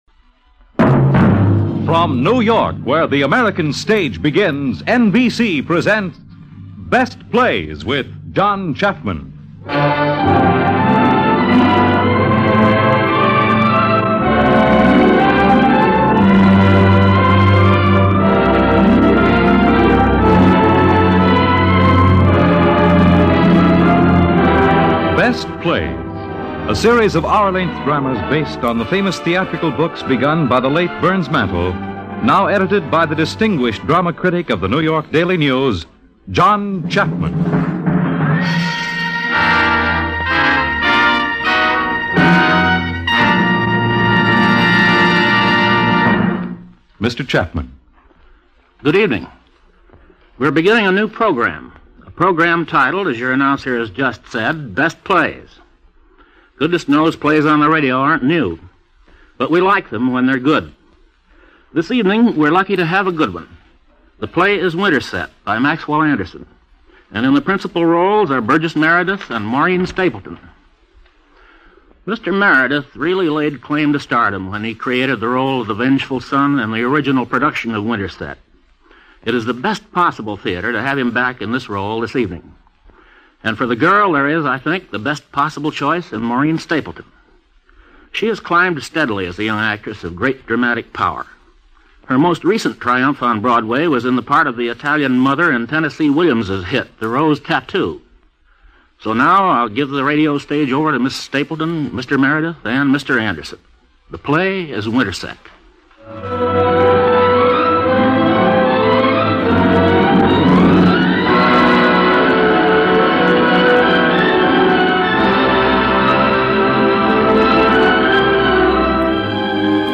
Going on-air from 1952 to 1953, the Best Plays was an NBC Radio program that featured some of the most excellent theatric plays ever created. Some of the best ones featured were dramatic or comedic plays.